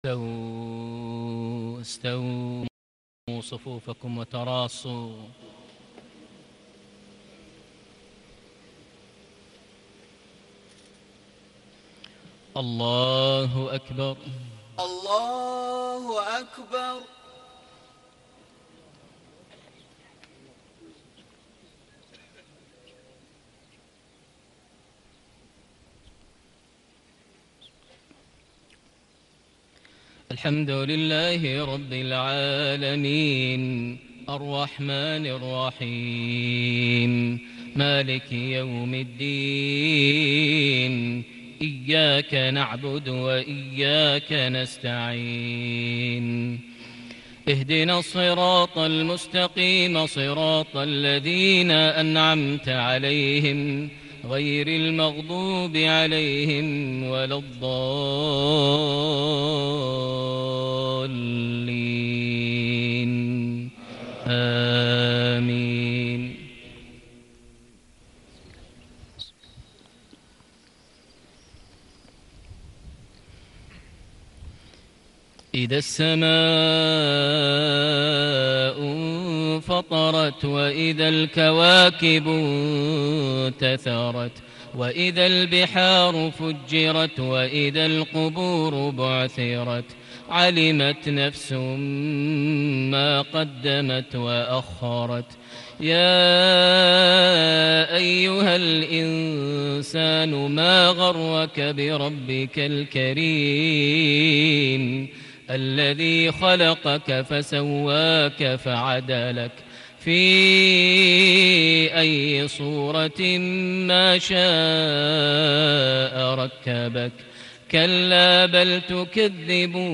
صلاة المغرب ٧ جماد الآخر ١٤٣٨هـ سورتي الإنفطار - الزلزلة > 1438 هـ > الفروض - تلاوات ماهر المعيقلي